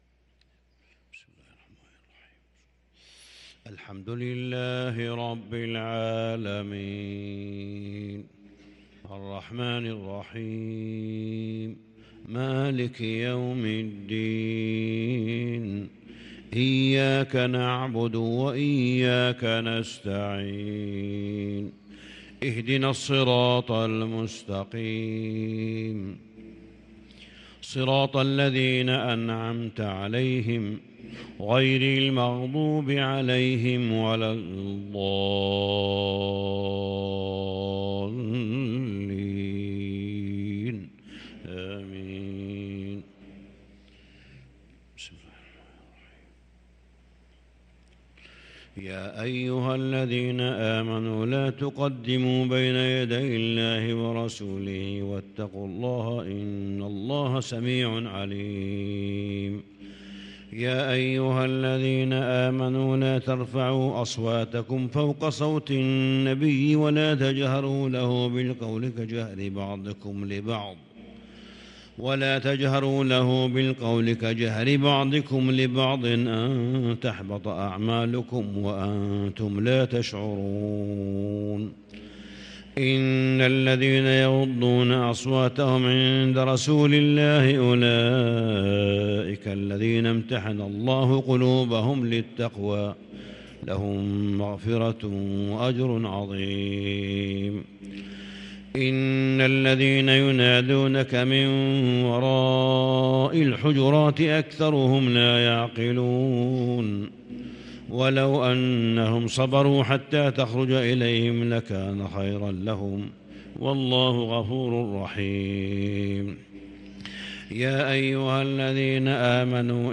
صلاة الفجر للقارئ صالح بن حميد 25 صفر 1444 هـ
تِلَاوَات الْحَرَمَيْن .